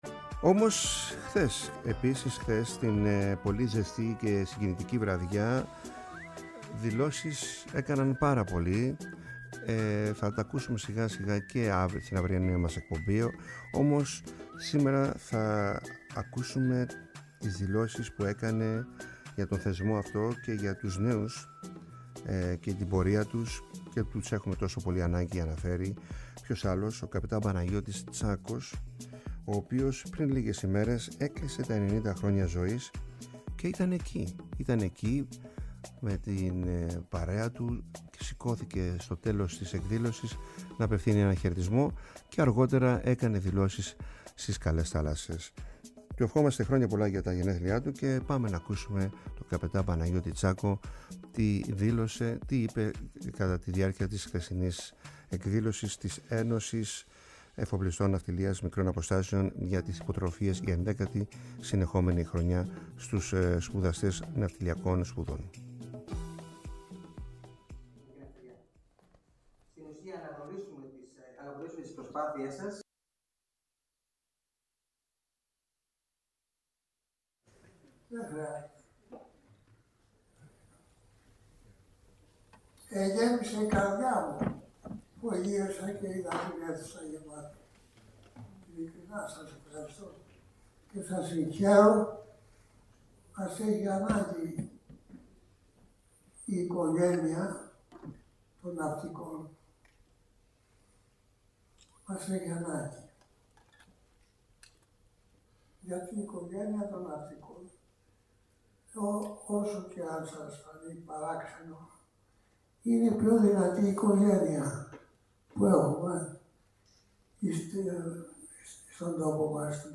μιλώντας στη διάρκεια της εκδήλωσης για τις υποτροφίες που χορήγησε η Ένωση Εφοπλιστών Ναυτιλίας Μικρών Αποστάσεων (ΕΕΝΜΑ)